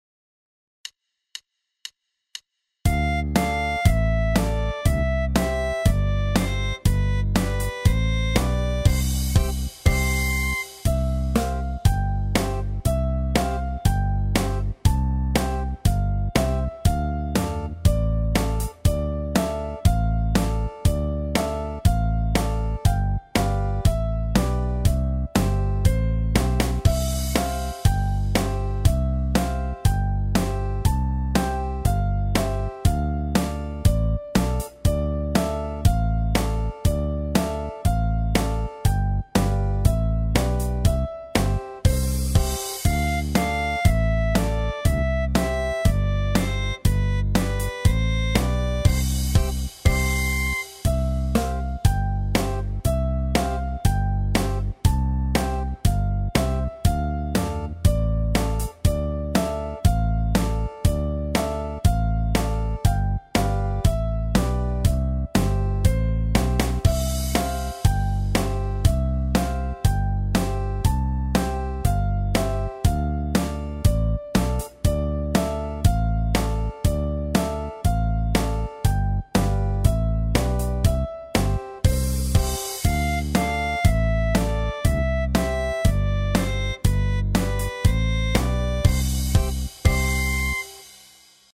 - aranżacje do ćwiczeń gry na dzwonkach: